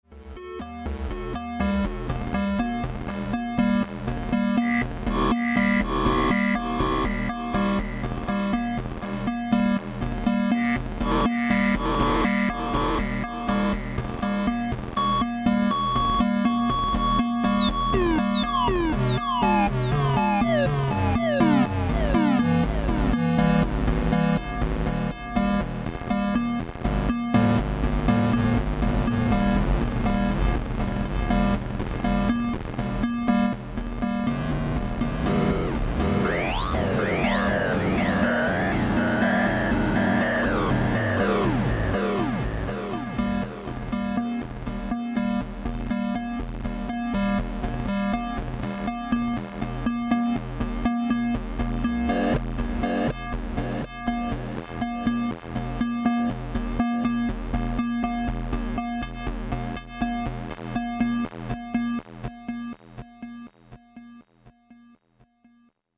prototype electronic musical instruments
Unlike the self destructing vacuum tube circuits the Barrons used, the Martian Music Machines are solid state integrated analog synthesizers similar to the instruments made during the mid 1960's by Bob Moog and Don Buchla. Many of the complex tonal modulation sounds seem to take on lives of their own as they sing or sometimes scream in and out of existence.